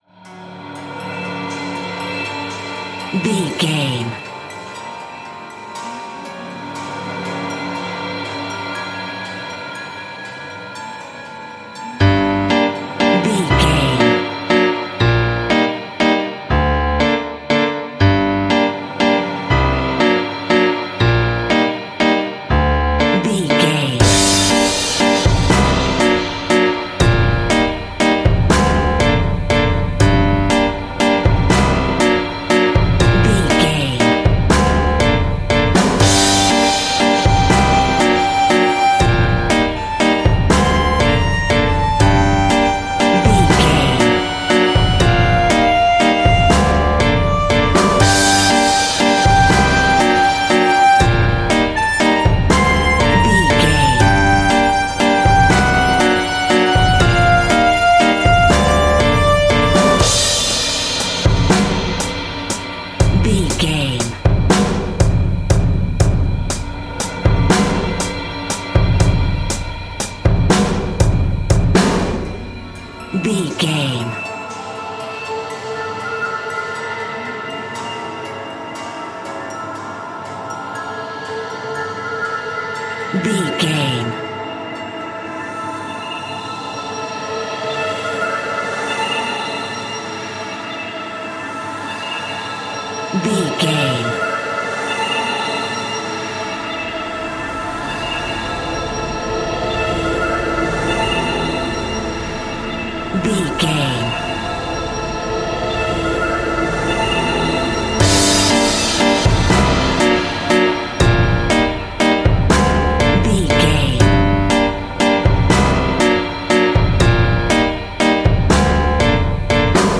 Aeolian/Minor
scary
ominous
dark
haunting
eerie
playful
strings
piano
drums
synthesiser
percussion
electric piano
pads